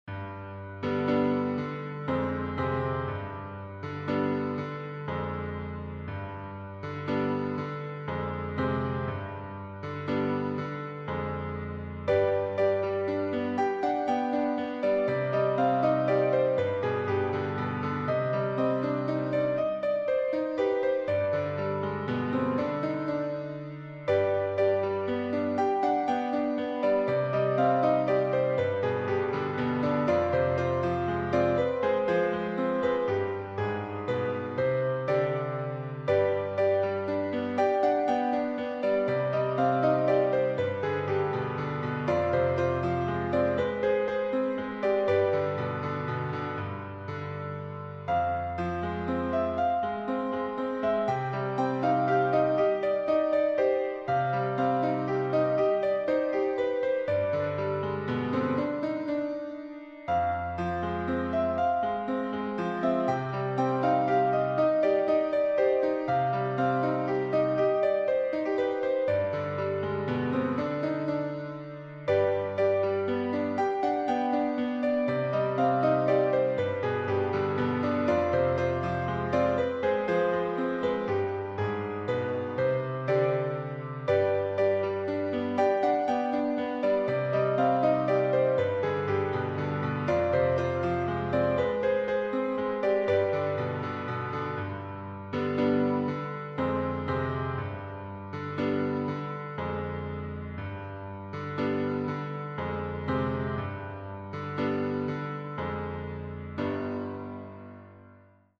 Müəllif: Azərbaycan Xalq Mahnısı